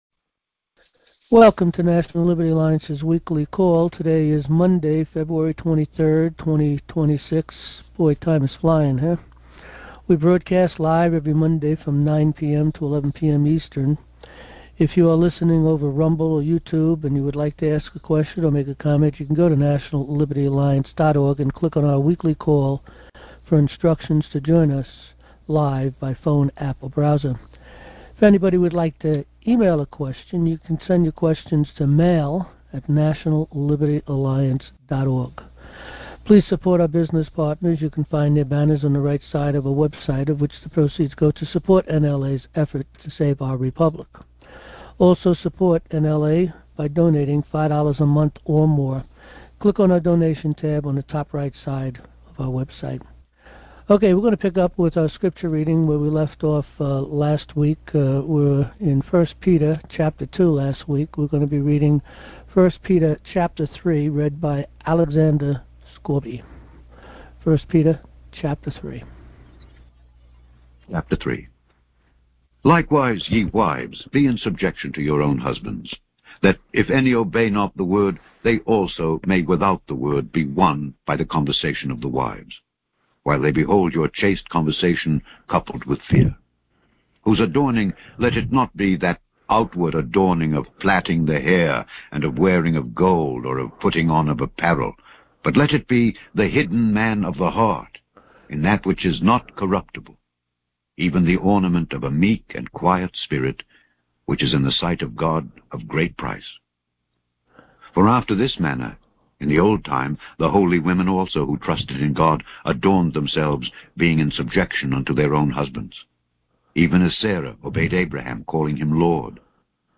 Monday Night Recordings
Monday - 9 PM-11PM Eastern time - Join National Liberty Alliance's Open Forum and weekly news and updates on NLA's advancements in the courts every Monday night.